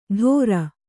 ♪ ḍhōra